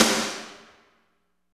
51.07 SNR.wav